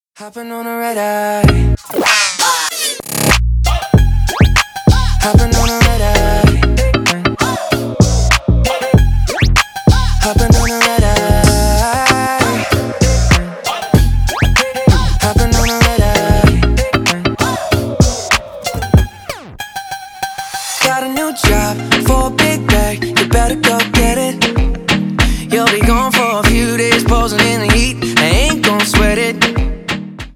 Поп Музыка
Электроника